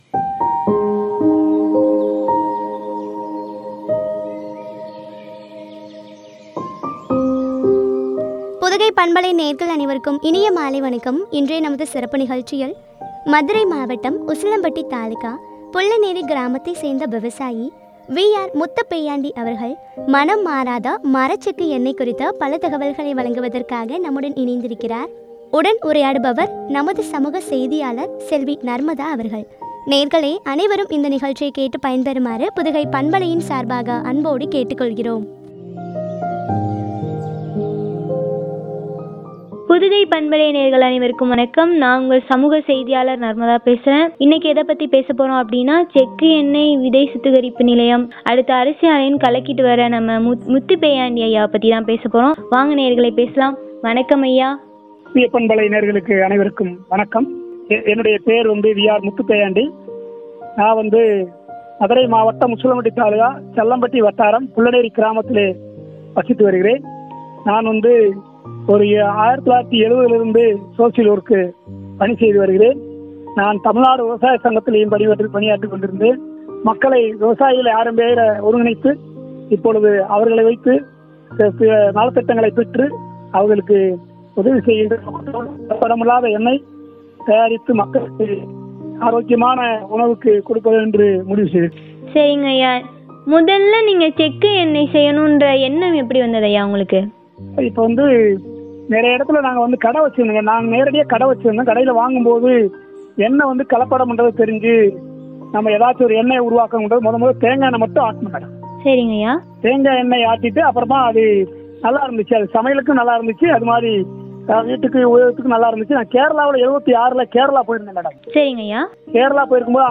மணம் மாறாத மரச்செக்கு எண்ணெய் பற்றிய உரையாடல்.